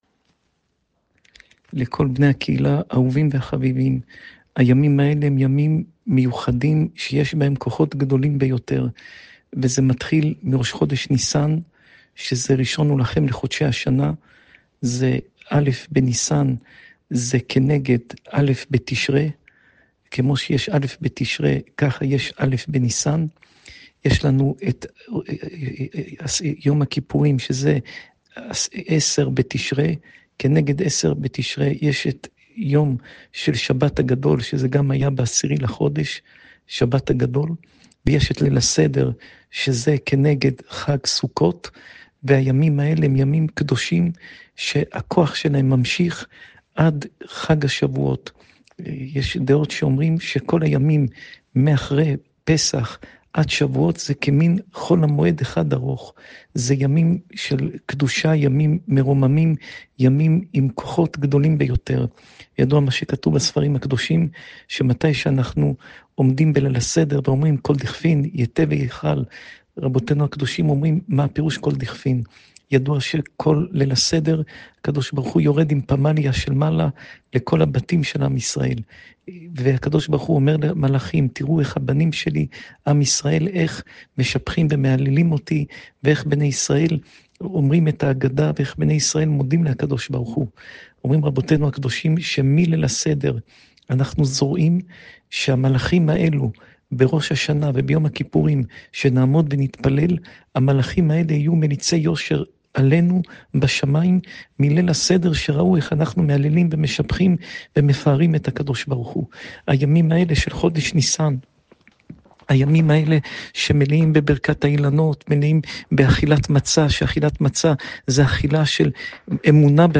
שעור תורה מפי הרב פינטו